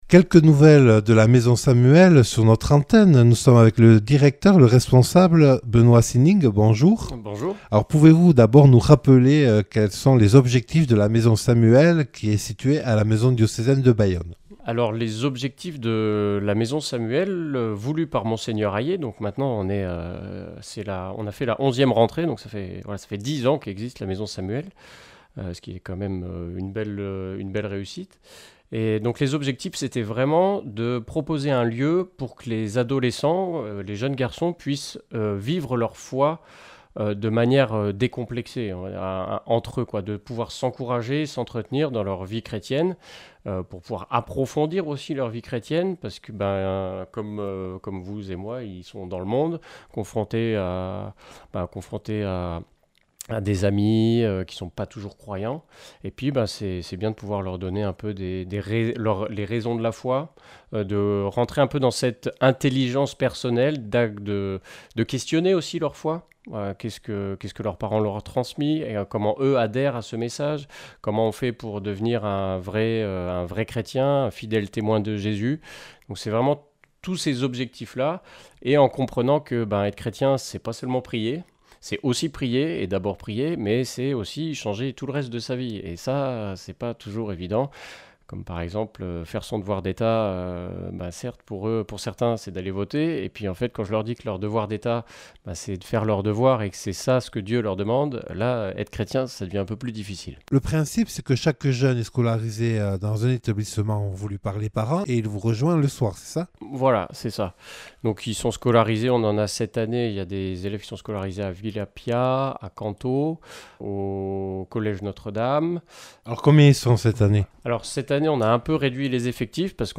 Interviews et reportages